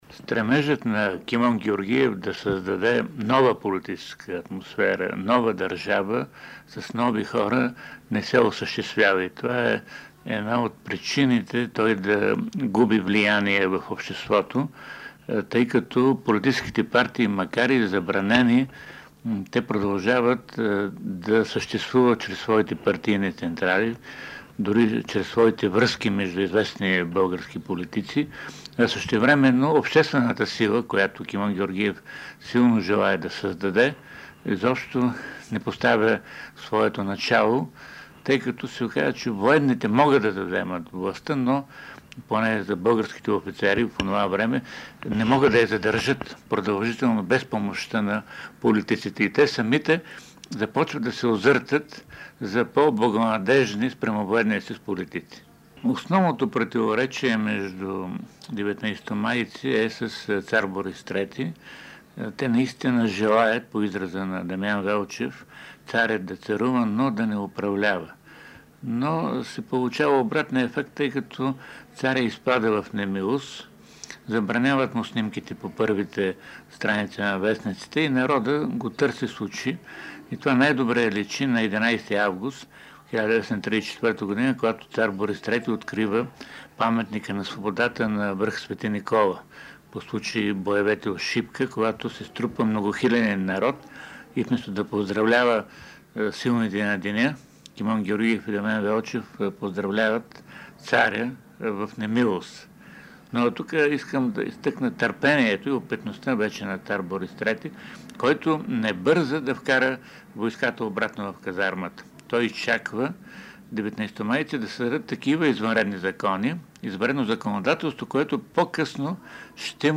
Предлагаме Ви няколко звукови документа, съхранени в Златния фонд на БНР, които представят аспекти от тези събития, както от преки свидетели и участници в тях, така и от дистанцията на времето и исторически поглед.